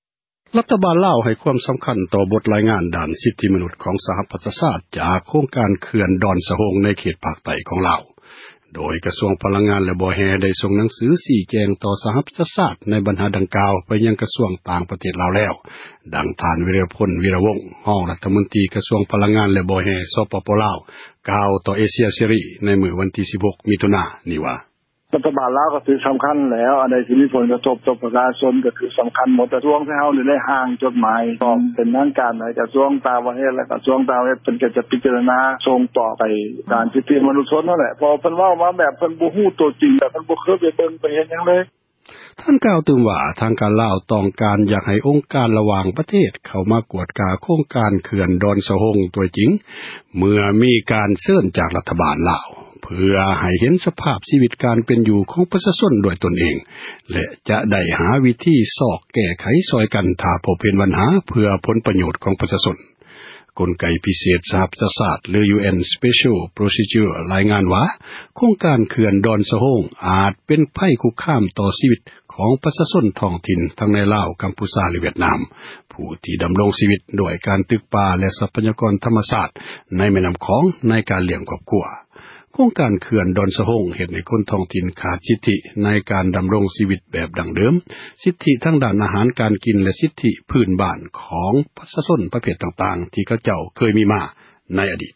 ດັ່ງ ທ່ານ ວິຣະພົນ ວິຣະວົງ ຮອງ ຣັຖມົນຕຣີ ກະຊວງ ພລັງງານ ແລະ ບໍ່ແຮ່ ສປປລາວ ກ່າວຕໍ່ ເອເຊັ ຽເສຣີ ໃນວັນທີ 16 ມິຖຸນາ ນີ້ວ່າ: